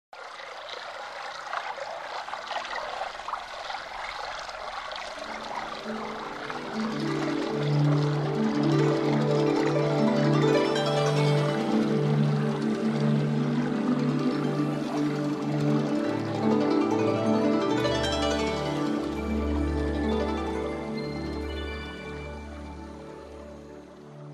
P O L I C E
water-sound_24668.mp3